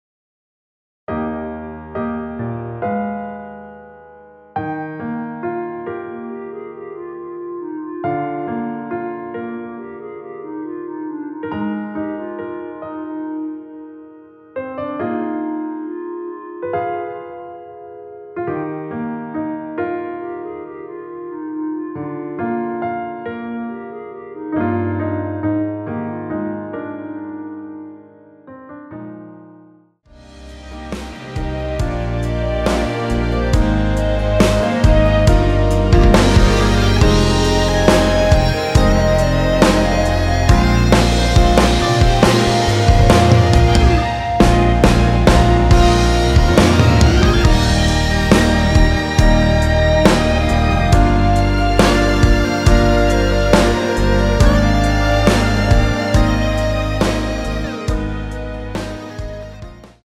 노래 들어가기 쉽게 전주 1마디 만들어 놓았습니다.(미리듣기 확인)
원키에서(-1)내린 멜로디 포함된 MR입니다.
Eb
앞부분30초, 뒷부분30초씩 편집해서 올려 드리고 있습니다.